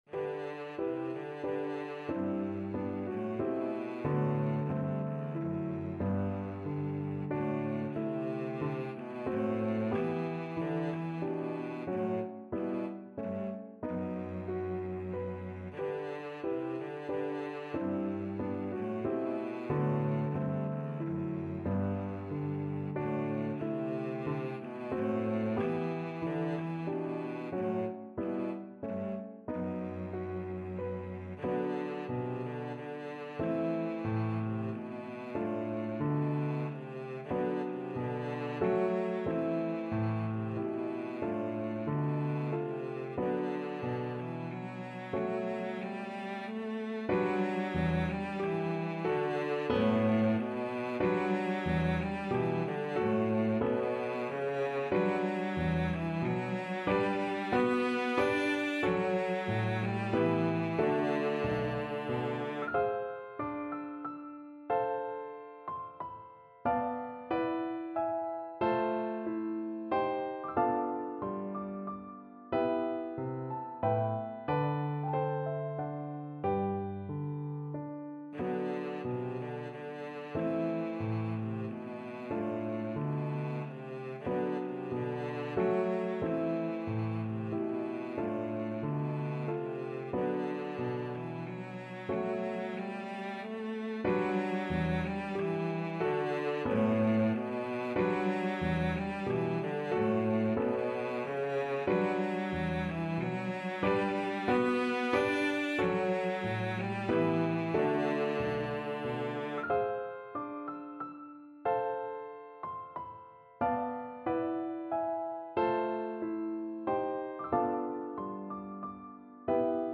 Cello version
Allegro moderato =92 (View more music marked Allegro)
3/4 (View more 3/4 Music)
Classical (View more Classical Cello Music)